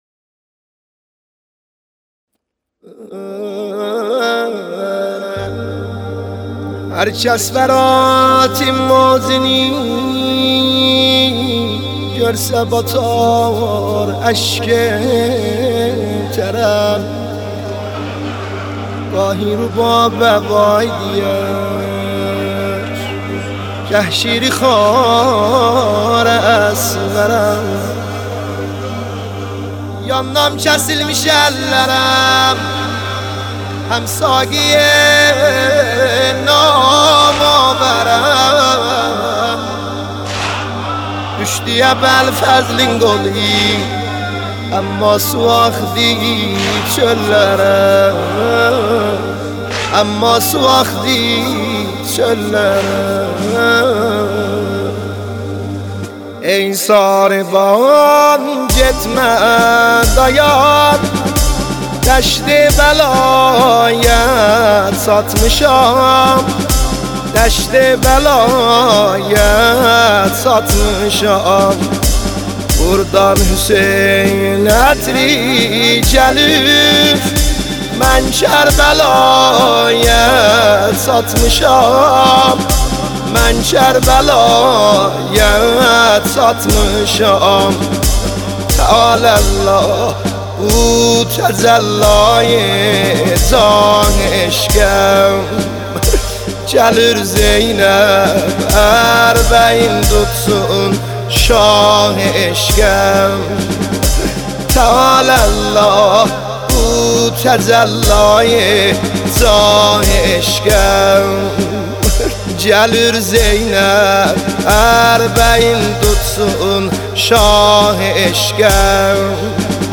مداحی ترکی